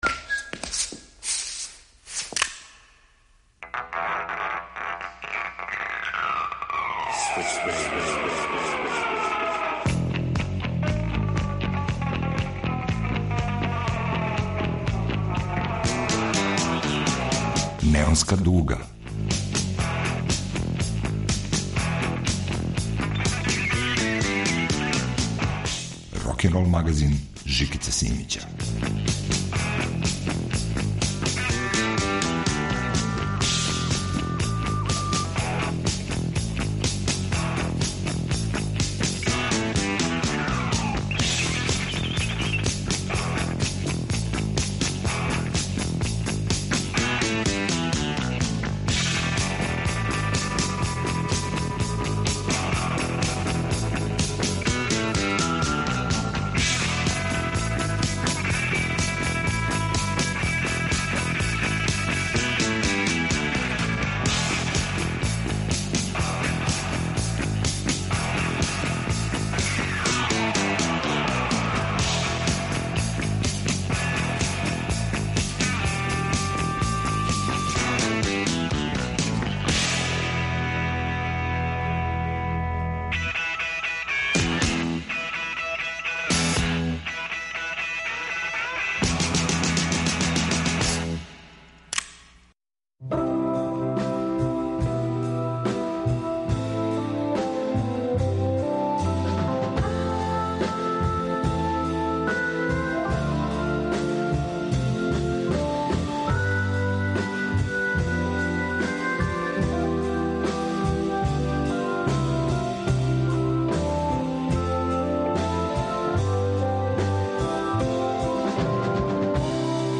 Неонска дуга се опрашта од ових музичара. Два туцета тужних песама за хероје који су отишли.